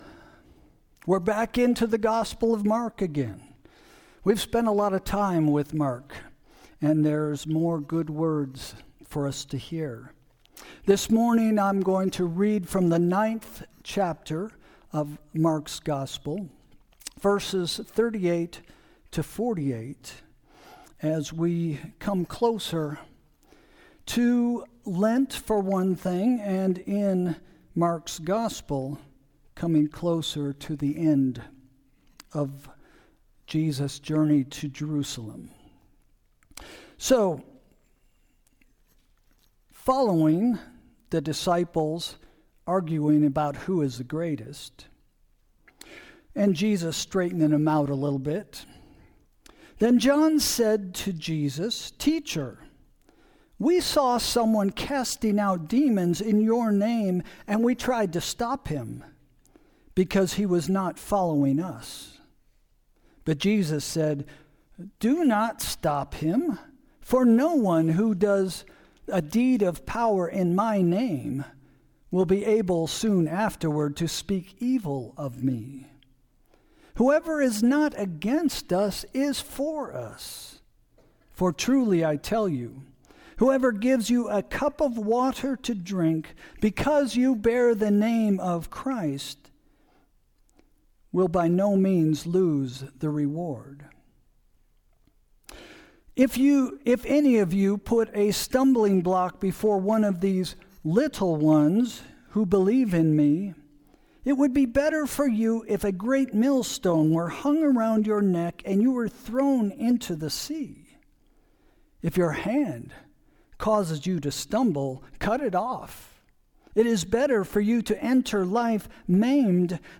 Sermon – February 8, 2026 – “Don’t Boomerang” – First Christian Church